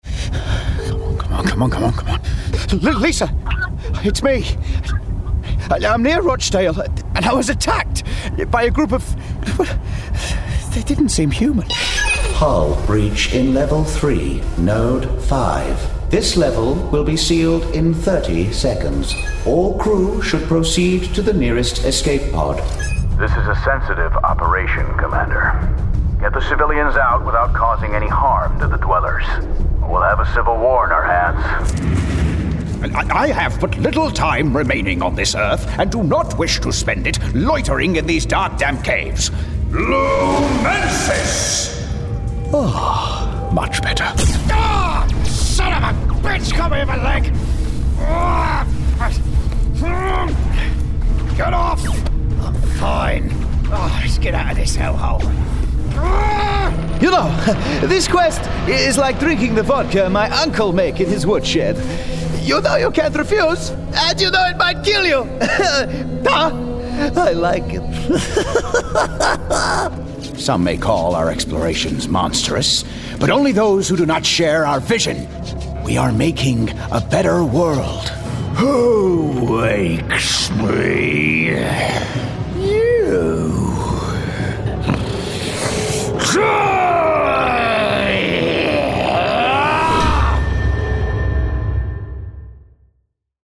Video Games
Gaming Showreel
Male
Cheshire
Northern
Confident
Cool
Playful
Reassuring